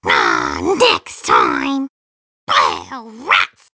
One of Toad's voice clips in Mario Kart 7